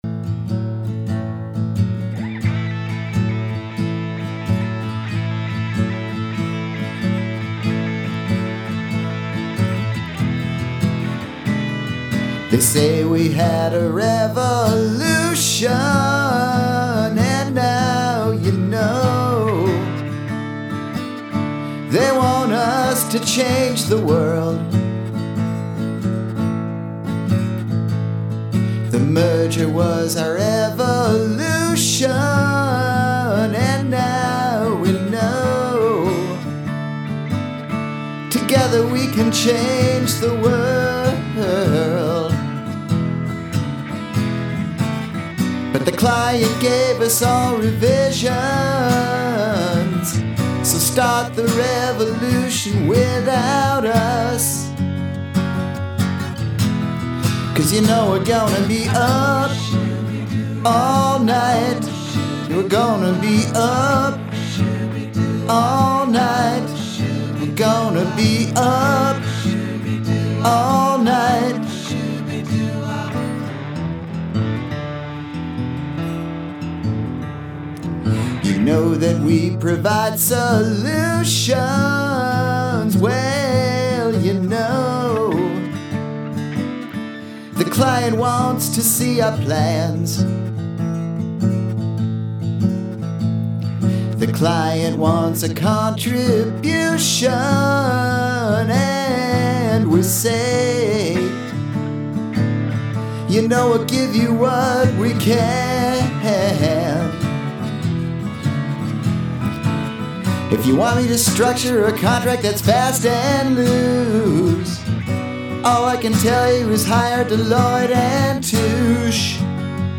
Lastly, here’s some old CPI/PW music we had during the party, along with the words to the song we sang.